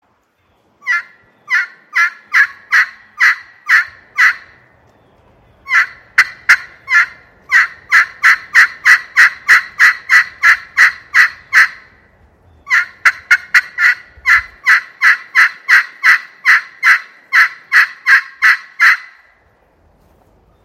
Canarywood Aluminum Pot Call
Includes (1) Two-Piece Striker
Aluminum